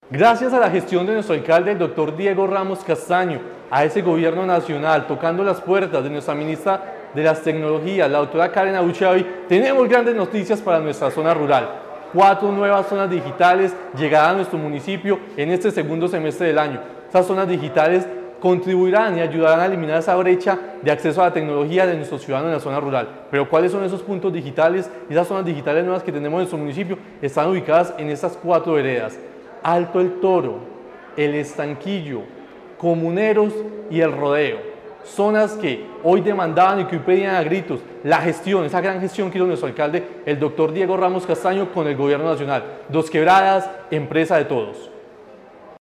Comunicado-271-Audio-Andrés-Felipe-Aguirre-Secretario-de-Asuntos-Administrativos.mp3